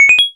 menuhit.wav